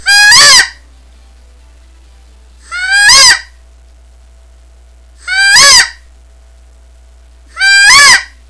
Listen to 9 seconds of peacock calls
• Loud, Reed-Type Locator Call With Volume-Enhancing Horn, imitates the sounds of a peacock, and pileated woodpecker...all of which tortures toms into gobbling in response so you know where they are.
qbpeacock9.wav